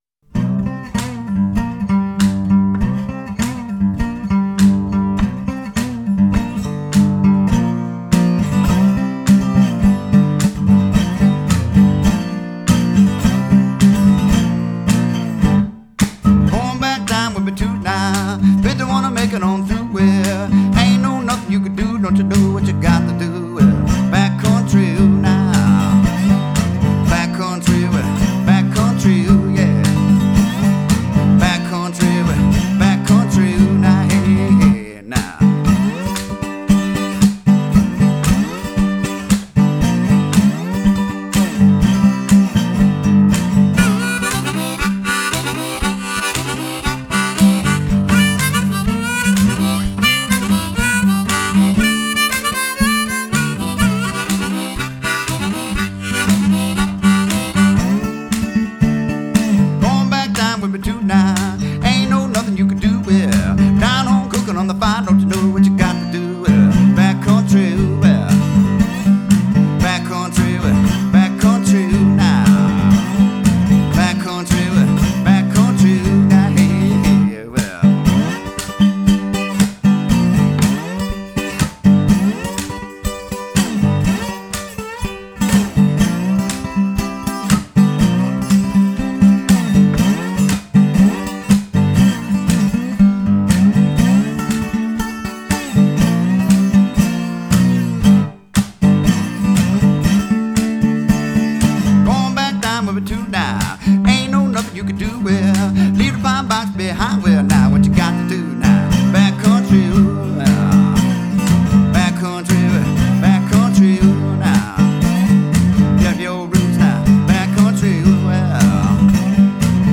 studio release
A must have for any early Blues fan.